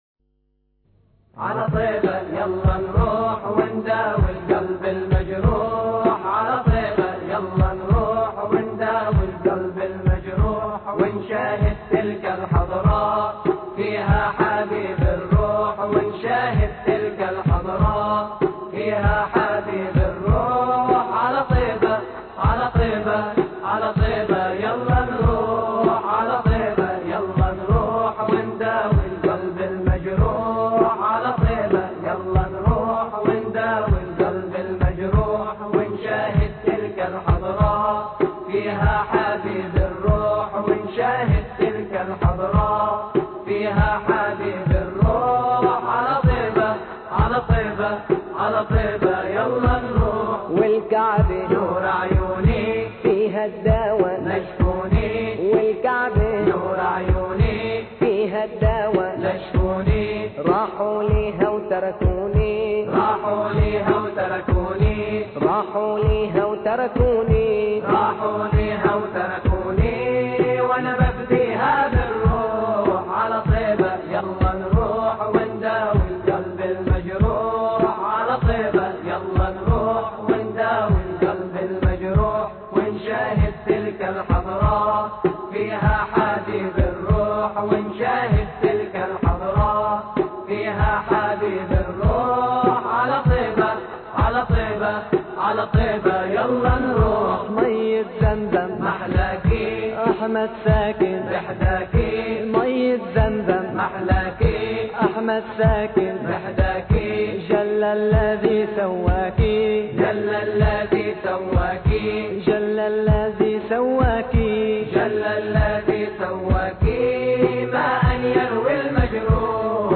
على طيبة يلّه انروح الأحد 31 مايو 2009 - 00:00 بتوقيت طهران تنزيل الحماسية شاركوا هذا الخبر مع أصدقائكم ذات صلة الاقصى شد الرحلة أيها السائل عني من أنا..